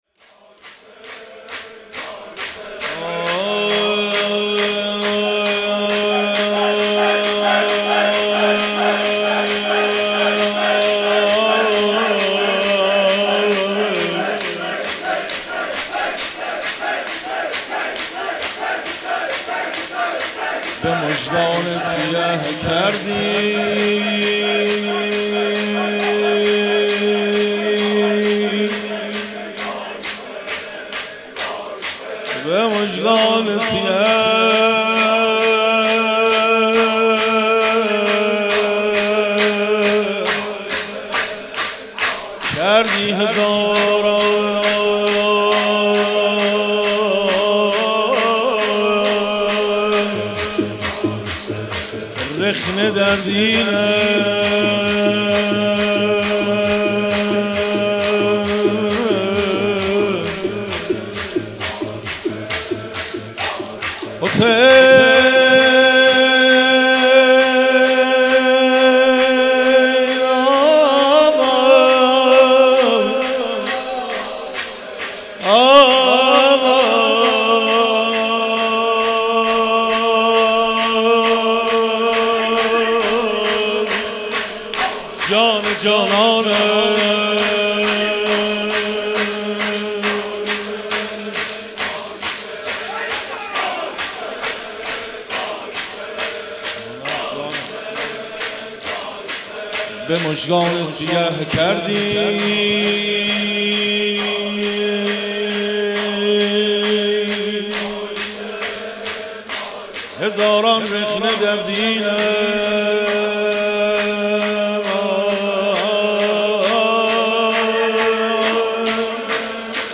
مداحی شب اول محرم
در حسینیه نور البکا- کربلای معلی
shab1-04-shoor.mp3